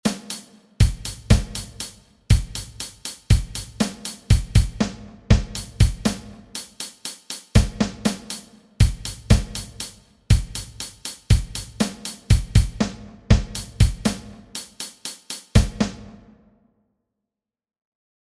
Alle Noten werden daher zufällig ausgewürfelt. Das Zeitraster ist 8-tel Noten, die Note kann da sein (1) oder nicht (0).
Das ergibt folgende 4 Takte mit den 3 jeweils zufällig auftretenden Noten der 3 Stimmen (HH, SN, BD).
Wie man hört, ist jegliche zeitliche Orientierung verlorengegangen Reiner Zufall Anhänge Geräusche-Ansatz.mp3 284,9 KB · Aufrufe: 227